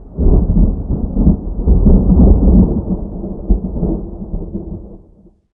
thunder36.ogg